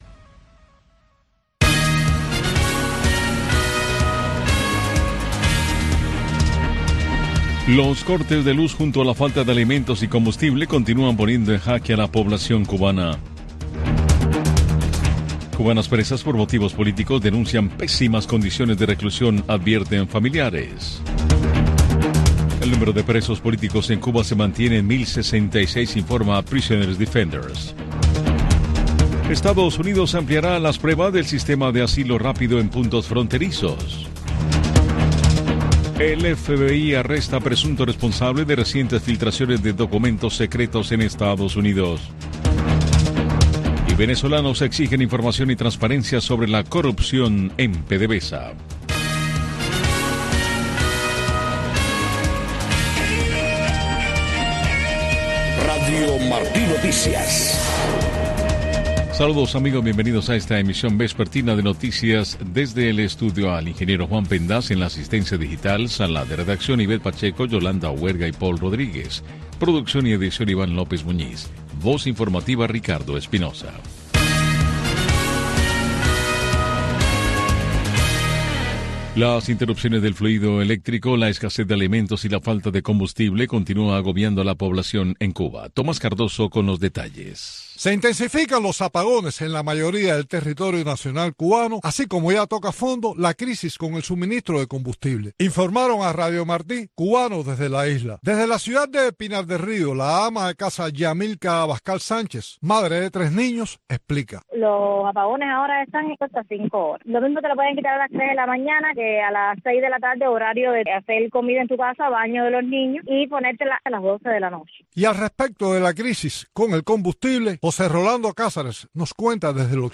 Noticiero de Radio Martí 5:00 PM | Primera media hora